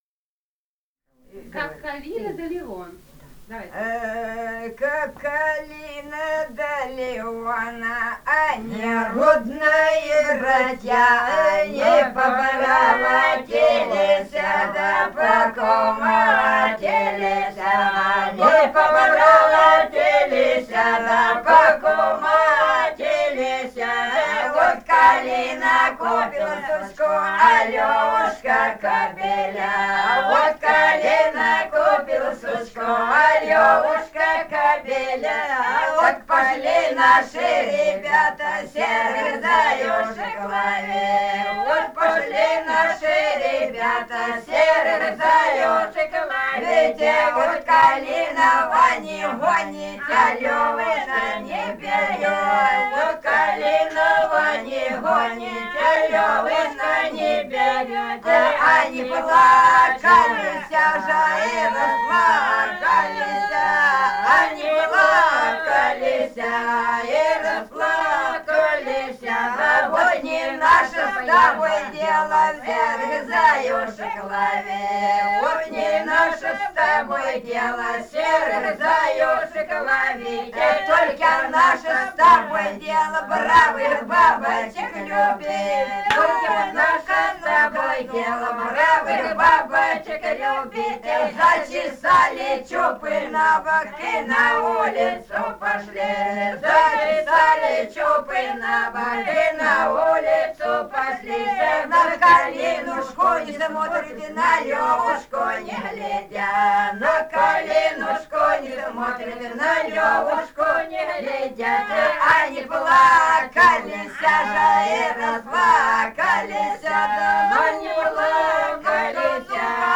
Свиридова И. К. Этномузыкологические исследования и полевые материалы 229. «Как Калина да Левон» (шуточная «улишная»).
Ростовская область, г. Белая Калитва, 1966 г. И0941-14